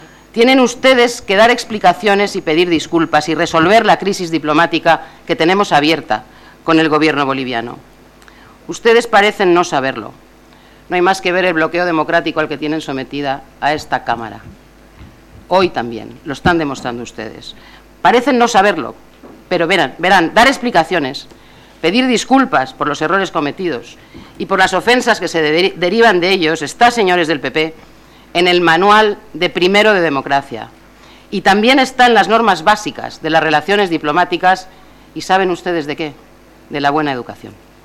Diputación Permanente del 11-07-13. Pide al gobierno que se disculpe ante los bolivianos por el incidente del avión presidencial y que se explique en este y e otros asuntos como el de Bárcenas